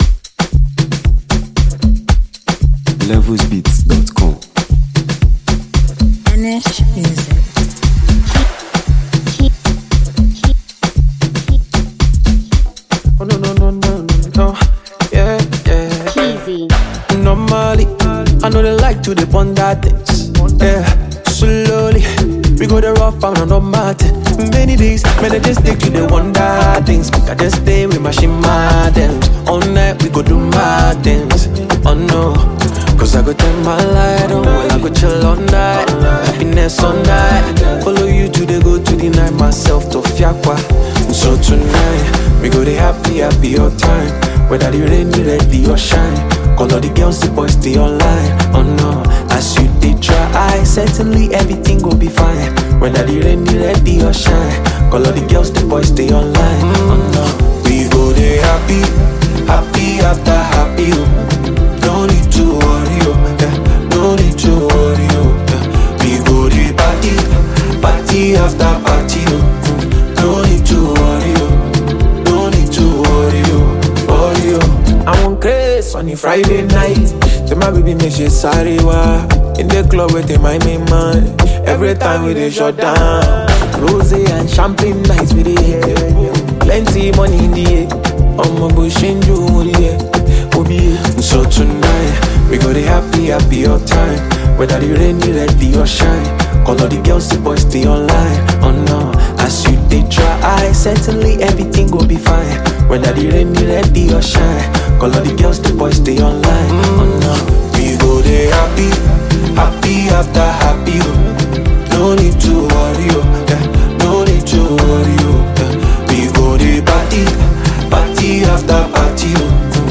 Ghana Music 2025 3:01